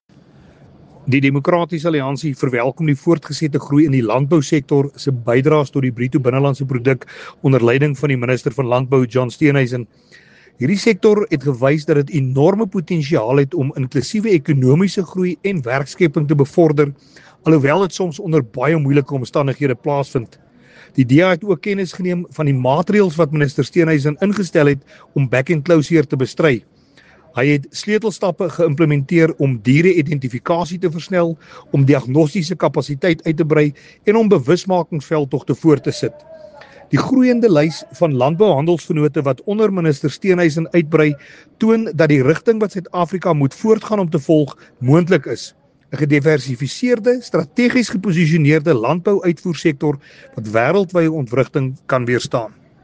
Afrikaans soundbites by Willie Aucamp MP.